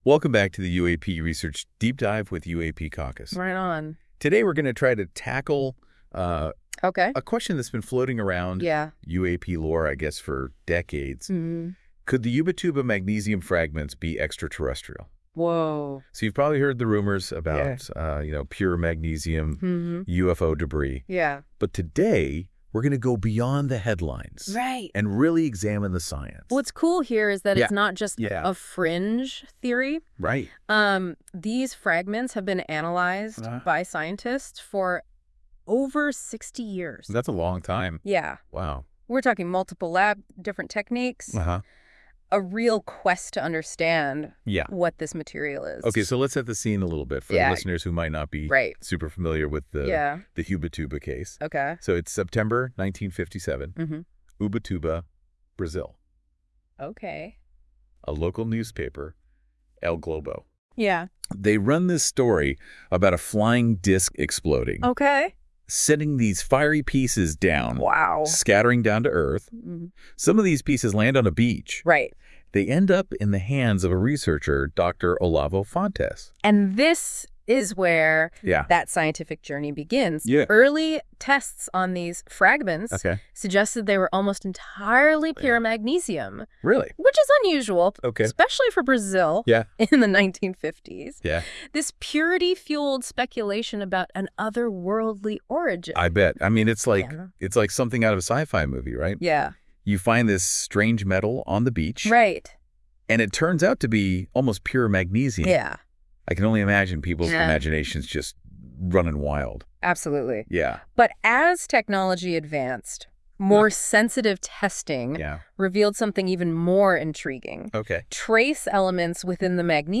This AI-generated audio may not fully capture the research's complexity.